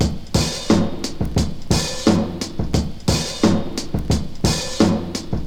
• 88 Bpm Drum Beat G Key.wav
Free drum groove - kick tuned to the G note. Loudest frequency: 1411Hz
88-bpm-drum-beat-g-key-3ue.wav